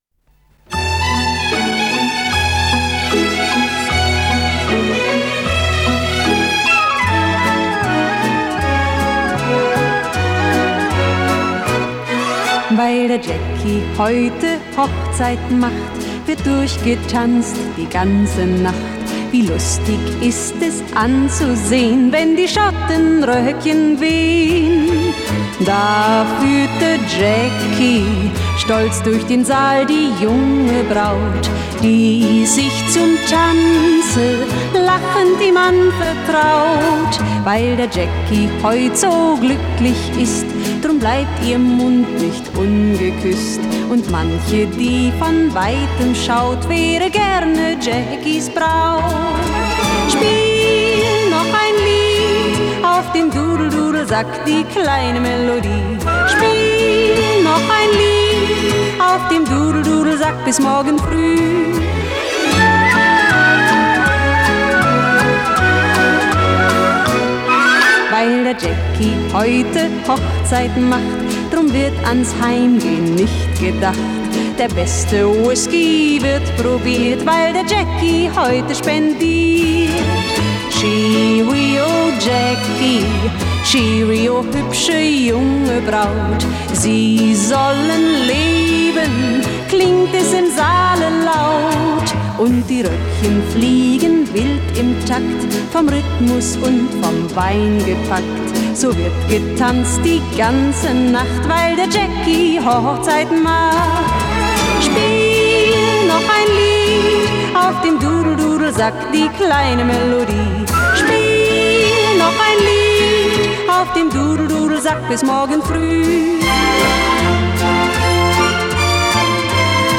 с профессиональной магнитной ленты
пение